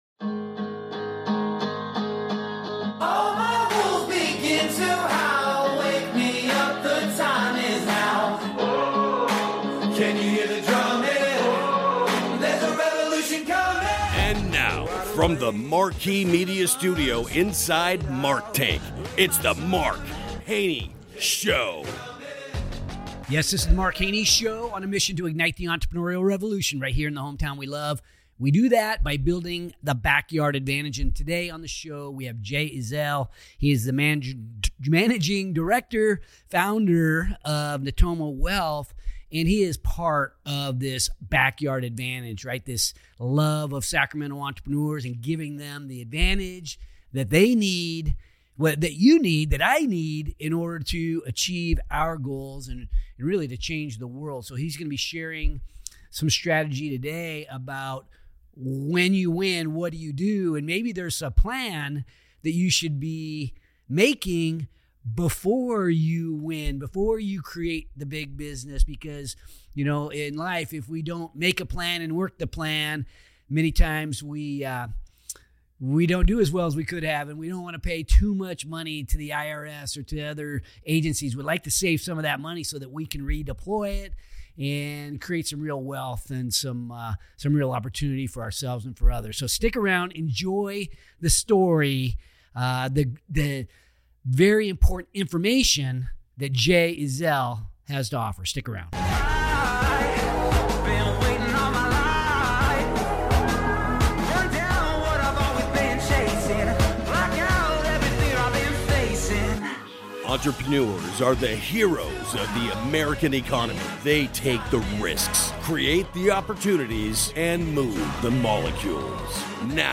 We'll dive into investment strategies that support your lifestyle and goals after selling your business, and highlight the benefits of Opportunity Zones. Also, learn the differences between financial and wealth advisors and how they can contribute to your success. Tune in to this eye-opening conversation